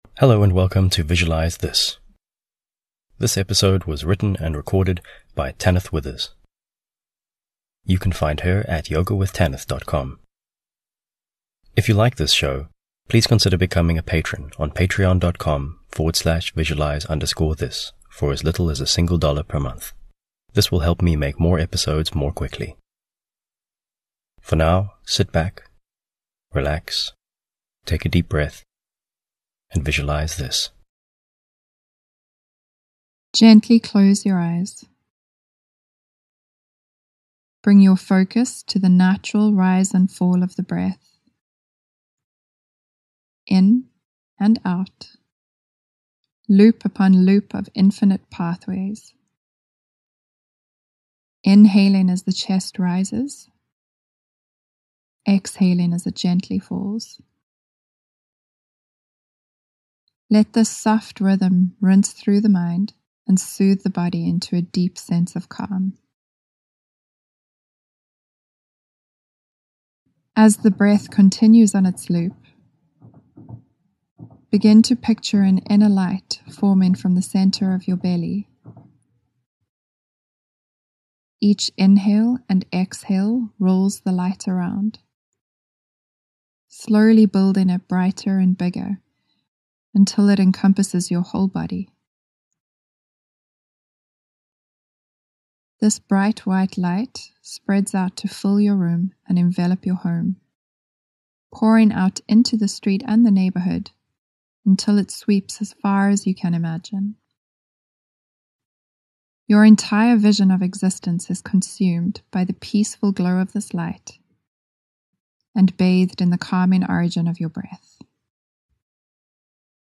The writing is evocative and I find the occasional rumble of the passing trains in the background very calming.